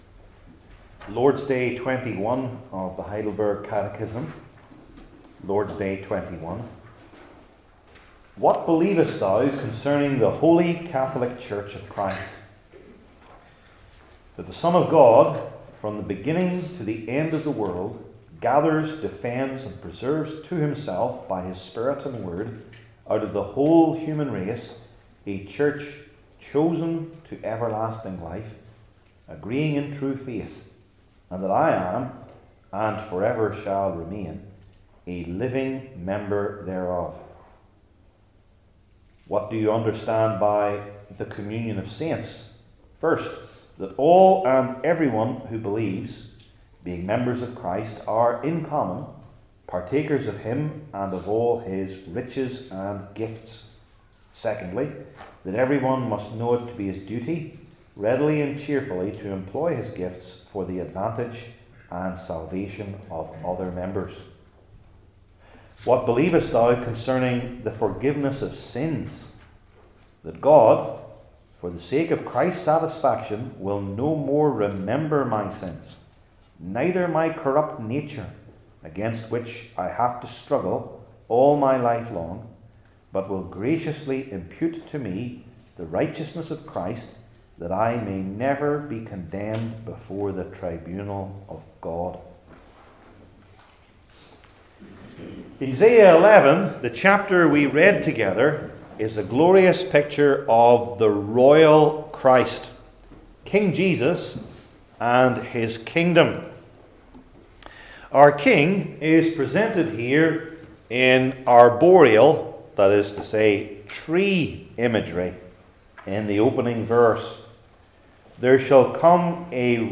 Heidelberg Catechism Sermons I. And Geography II.